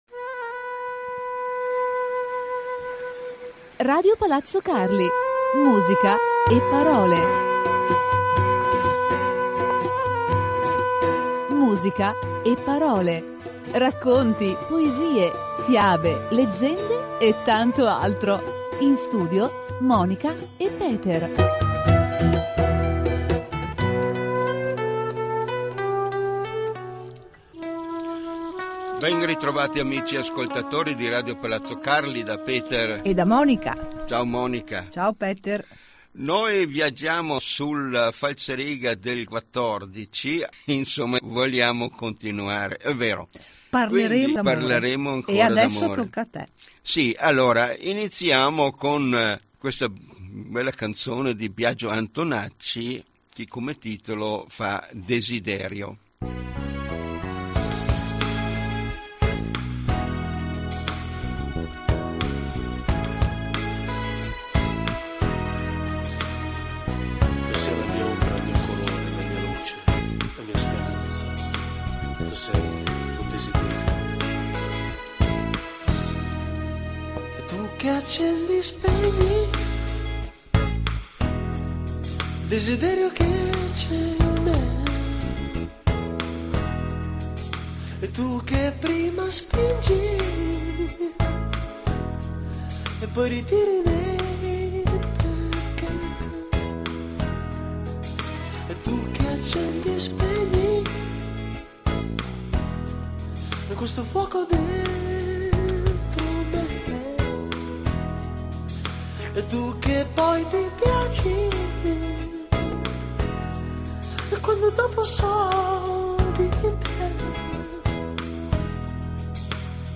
La coppia radiofonica più gettonata di RPC con la loro seguitissima trasmissione di favole, storie, leggende e poesie e tanto altro e, come sempre, il tutto inframmezzato da ottima musica dal mondo.